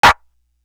Mpk Clap.wav